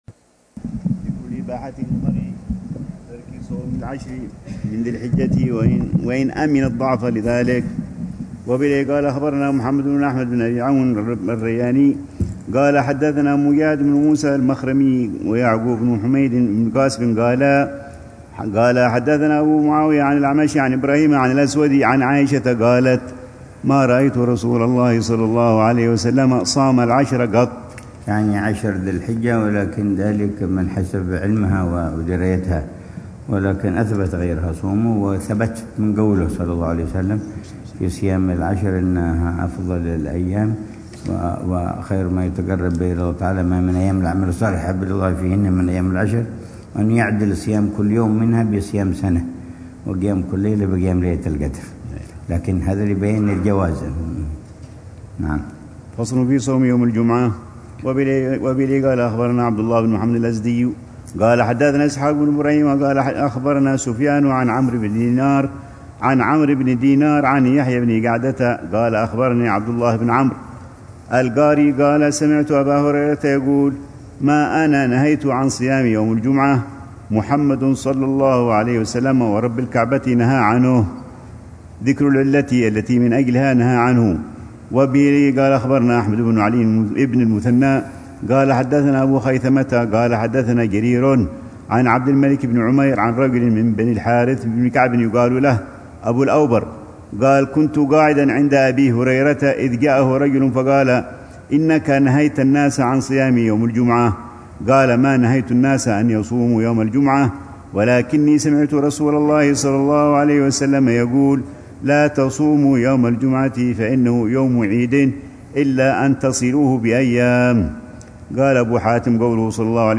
الروحة التاسعة والعشرون بدار المصطفى أيام الست من شوال لعام 1446هـ ، وتتضمن شرح الحبيب العلامة عمر بن محمد بن حفيظ لكتاب الصيام من صحيح ابن حب